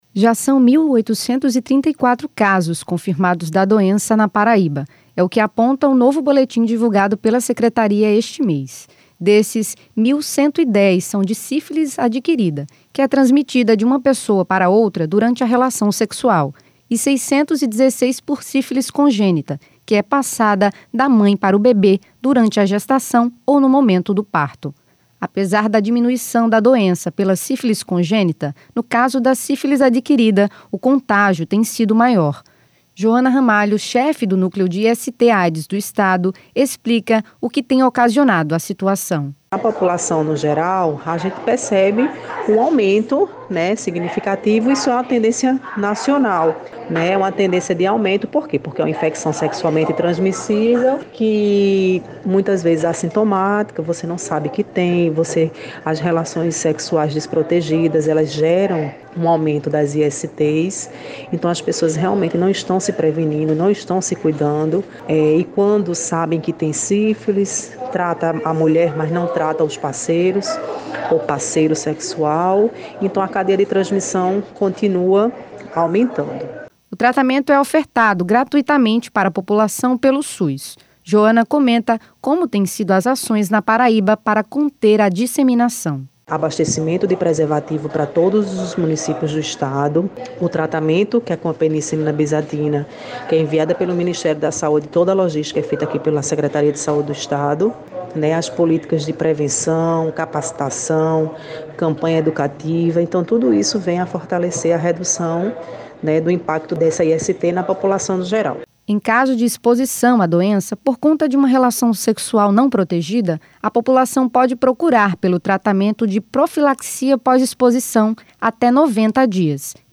Reportagem: tratamentos e serviços contra sífilis são oferecidos na capital